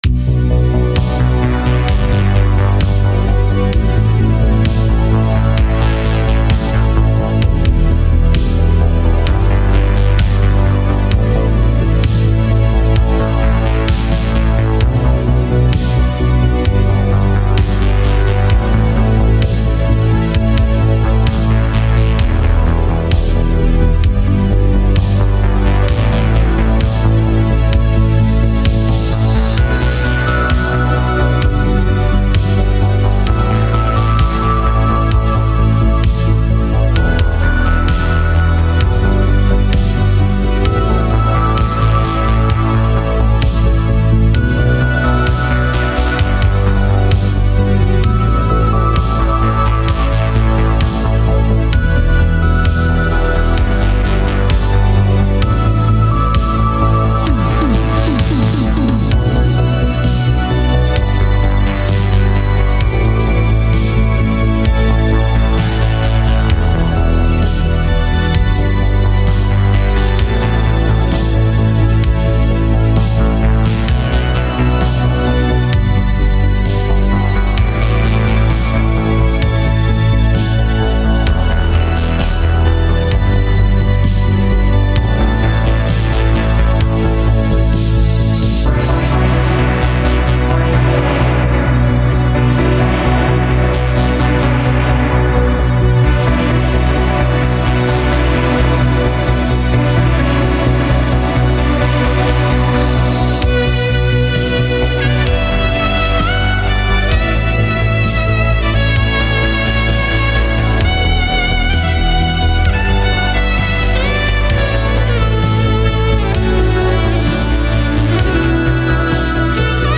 This is why I play piano, synth, guitar and sing on my own.
This unmixed song is one of those drafts.